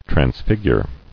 [trans·fig·ure]